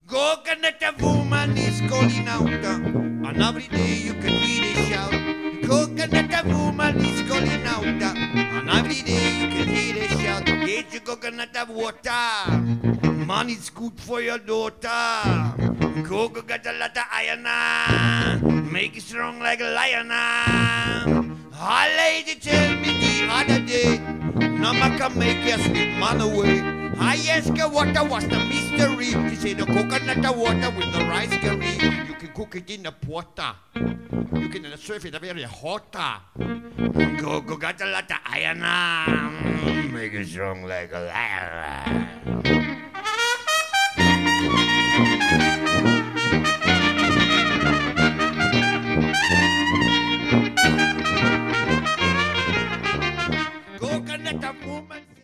Trompete, Flügelhorn
Stromgitarre, 5-string-Banjo
Hörbeispiele (Live)